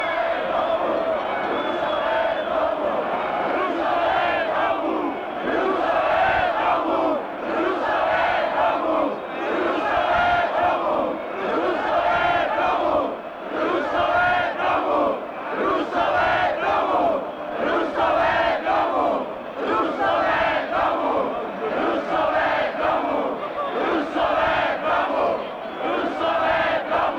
V Archivu bezpečnostních složek byla nedávno dokončena digitalizace pásků obsahujících zvukové nahrávky pořízené na Václavském náměstí (fond A 34 i.j. 2665).
Zajímavá je i poslední nahrávka pořízená přímo před Aeroflotem.
Před Aeroflotem